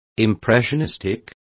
Complete with pronunciation of the translation of impressionistic.